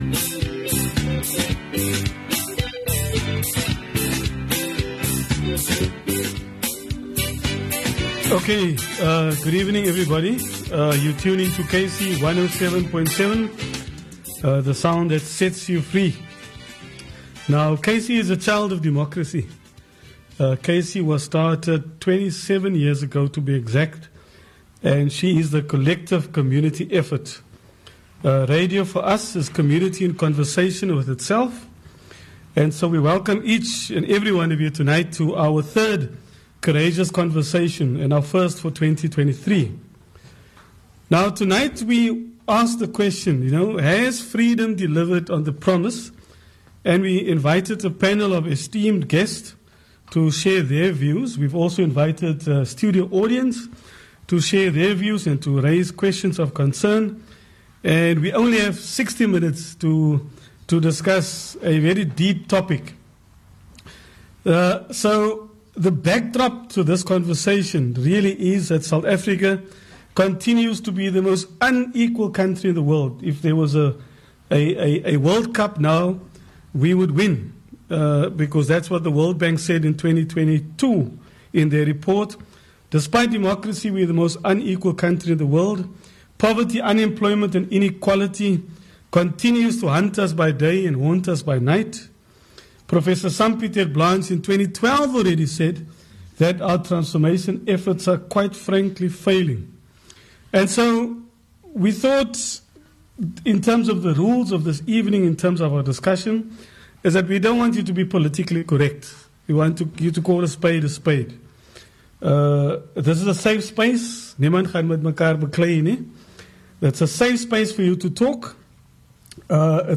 Beverley Schafer is the Deputy Speaker of The Western Cape legislature.